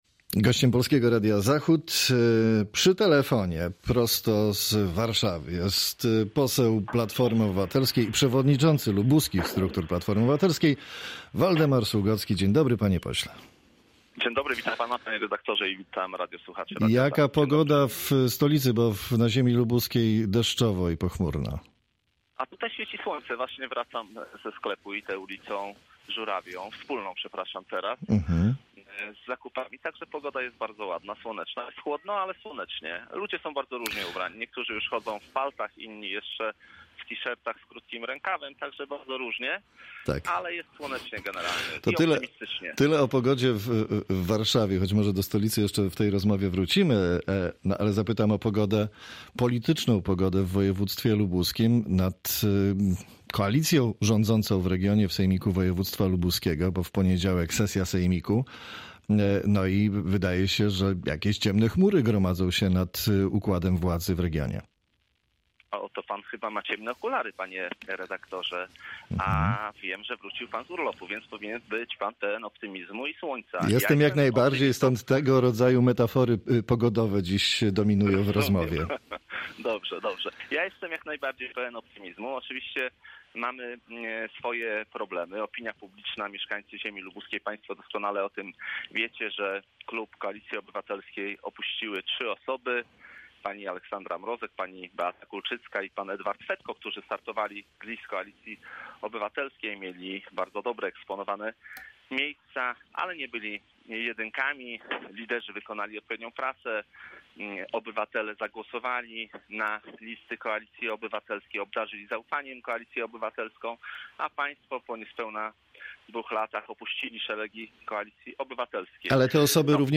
Z posłem PO, przewodniczącym partii w regionie lubuskim rozmawia